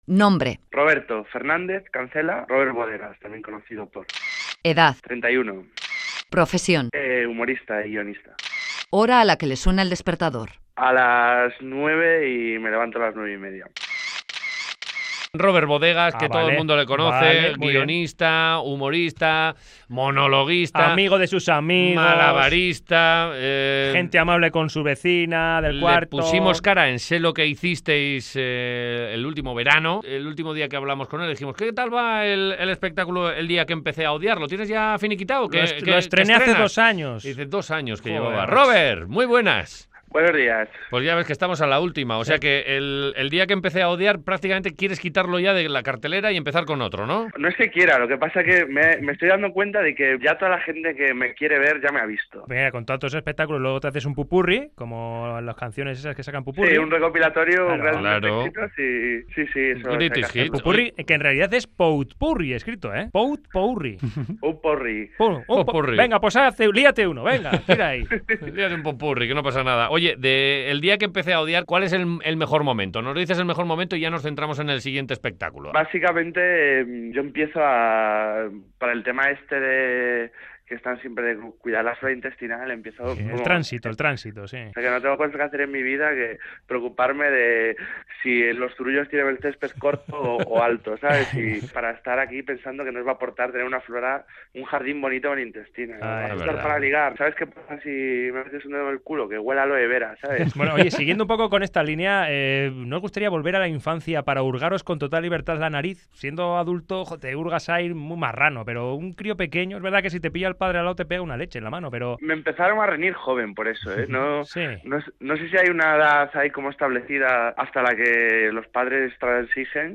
Entrevista con el humorista Rober Bodegas | Humor
El humorista Rober Bodegas cuenta un sueño 'húmedo' a Los Madrugadores